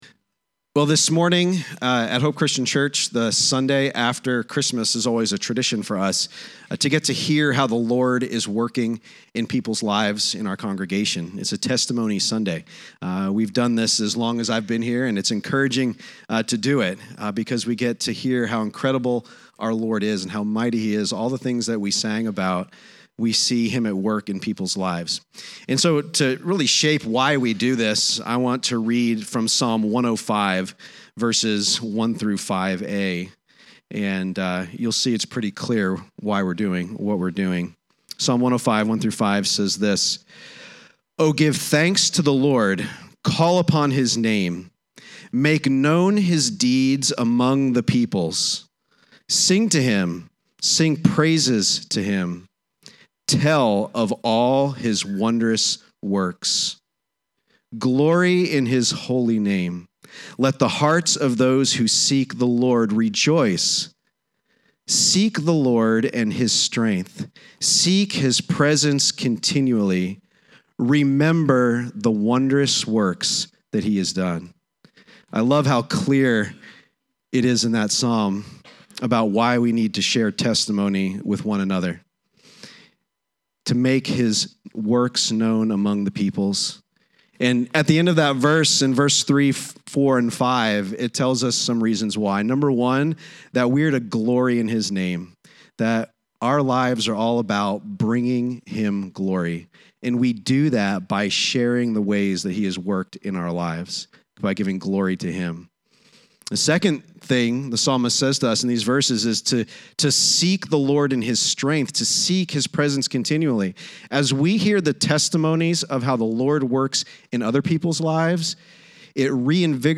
Testimony Sunday